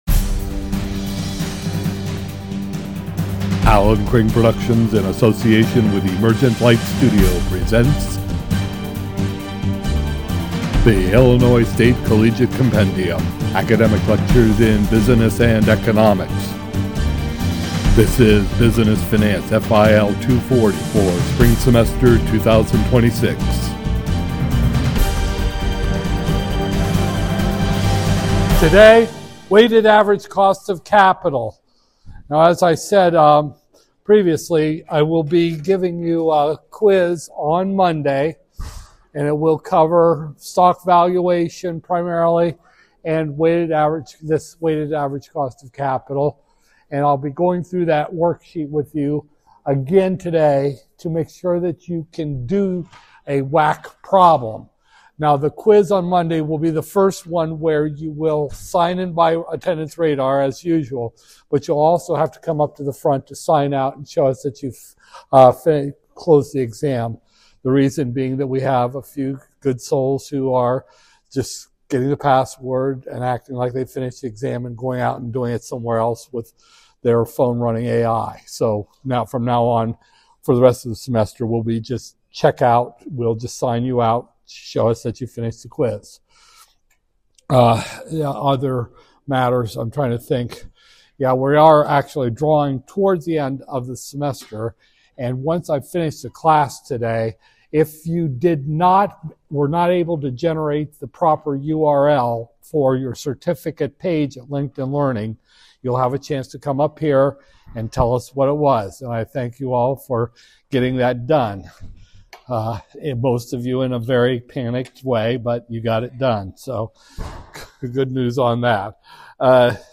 Business Finance, FIL 240-002, Spring 2026, Lecture 20